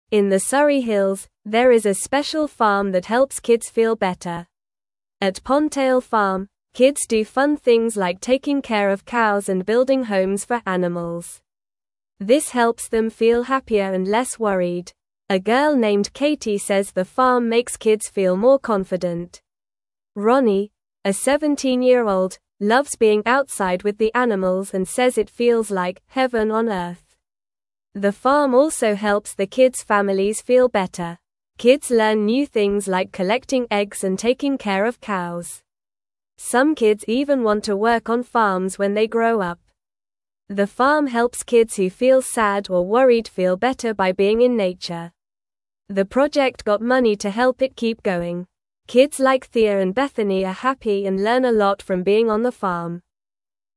Normal
English-Newsroom-Beginner-NORMAL-Reading-Happy-Kids-at-Pondtail-Farm-with-Animals.mp3